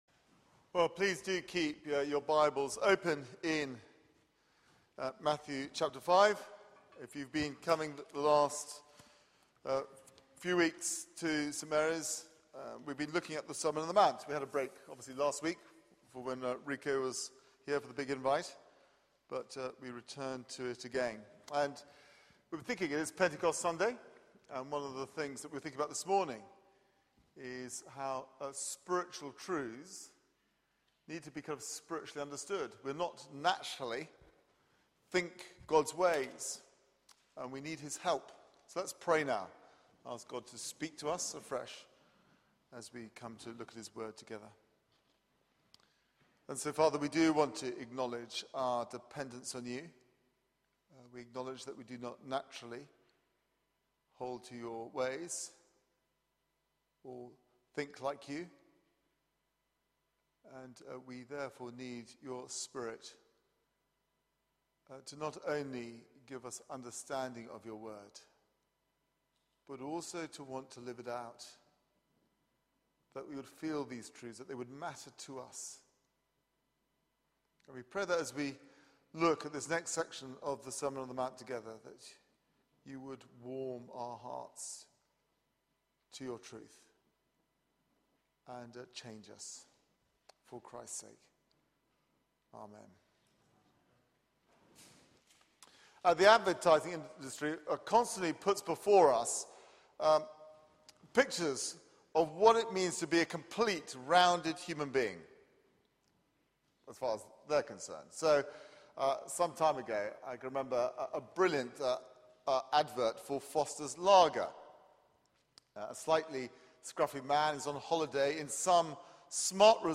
Media for 6:30pm Service on Sun 19th May 2013
Passage: Matthew 5:33-42 Series: The Masterclass: The Sermon on the Mount Theme: Truth and justice